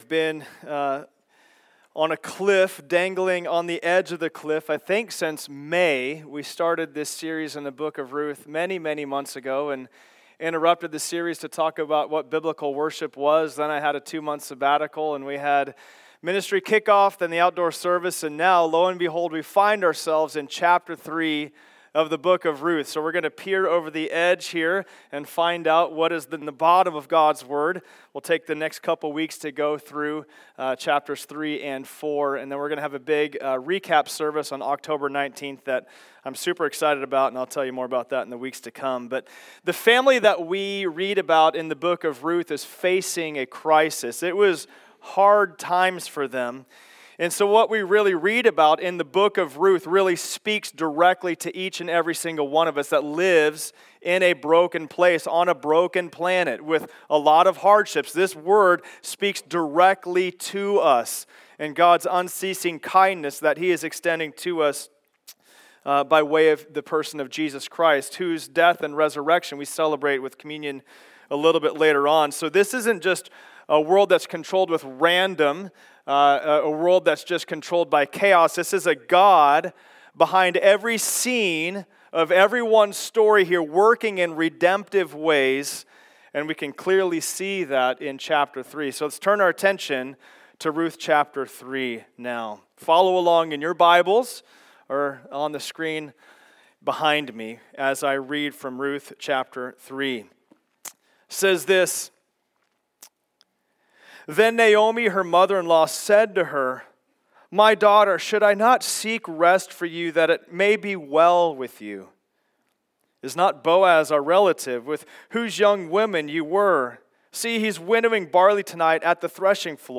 Ruth 3 Service Type: Sunday Service Download Files Notes « Sabbatical Recap 2025 Ruth 4